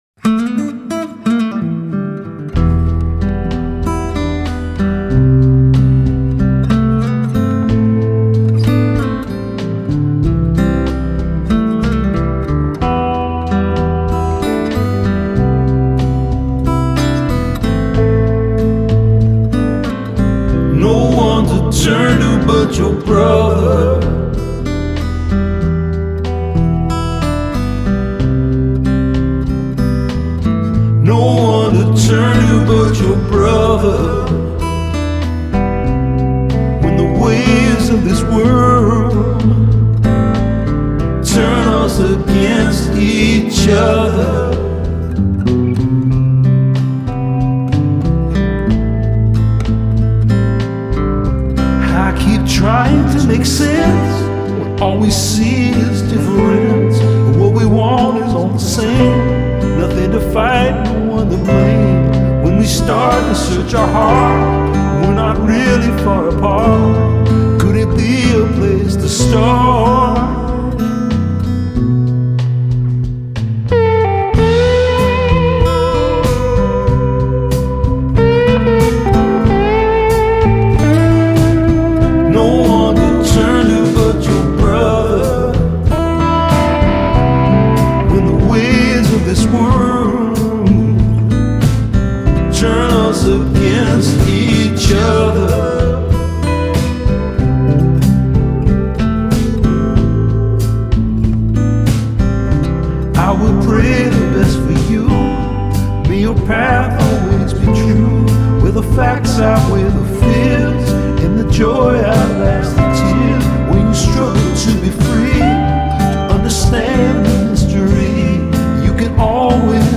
Канадская группа, работающая на стыке фолк-рока и блюза.